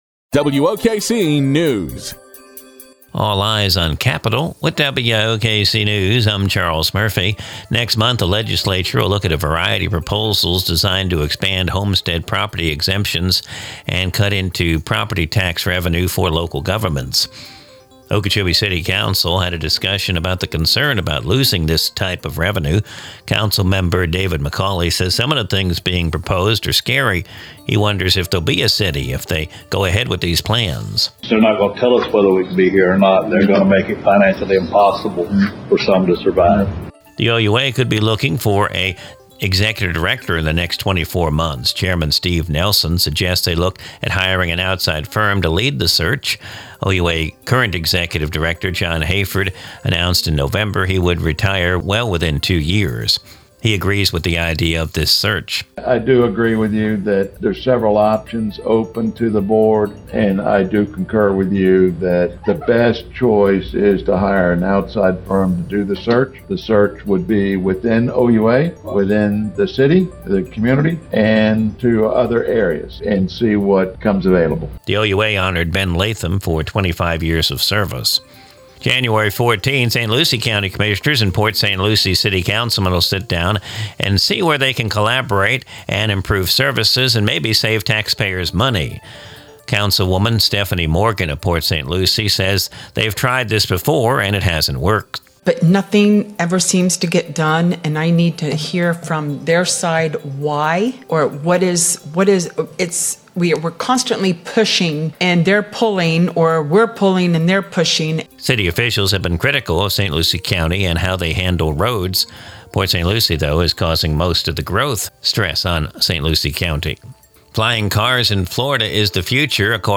Recorded from the WOKC daily newscast (Glades Media).